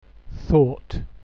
Many American accents also pronounce PALM, LOT and THOUGHT the same: